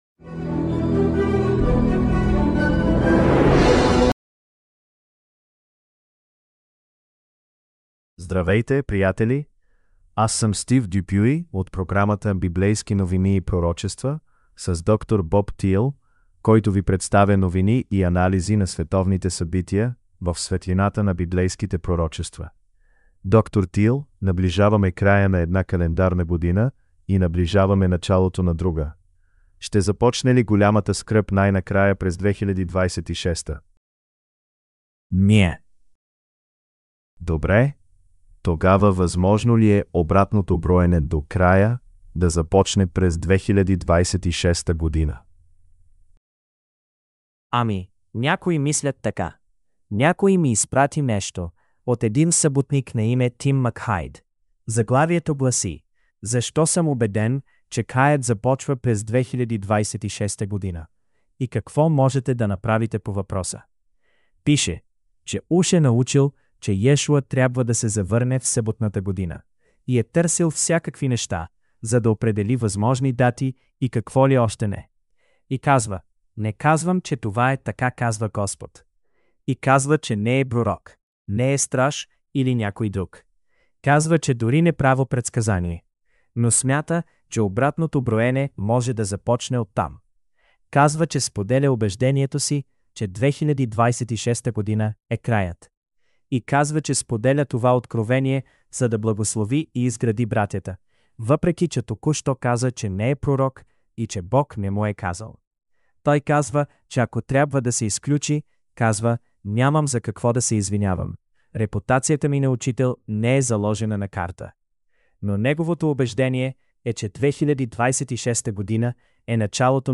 Bulgarian Sermonette – Bible Prophecy News